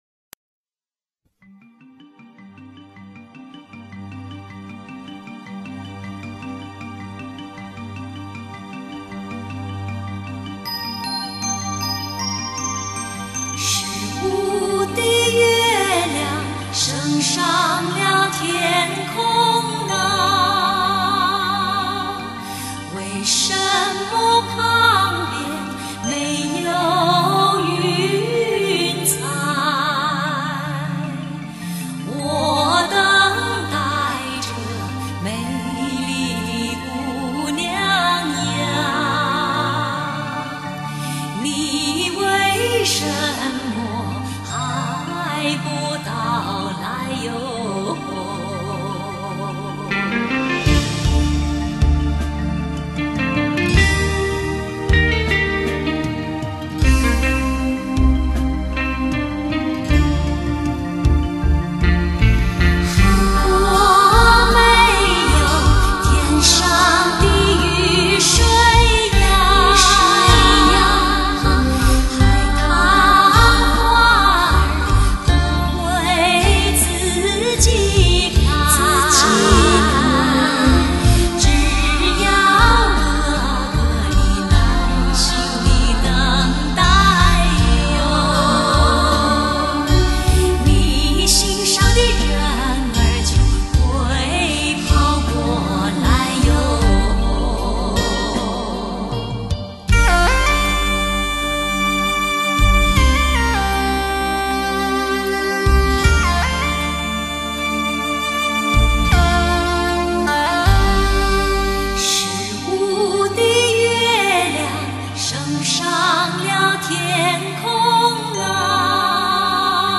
(双面黑胶版）
草原的歌，是悠扬的牧歌；草原的歌，是动人的情歌；草原的歌，像美妙的旋律飘向遥远的天边。
感受神秘西藏的强烈吸引，聆听来自西域佛国的天籁之音，豪迈，奔放，深情的旋律向你展示草原音乐的旖旎动人。